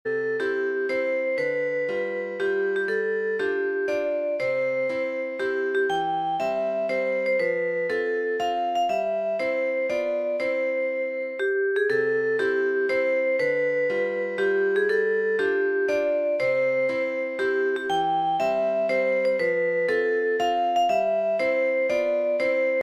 Marble bell sound music bell sound effects free download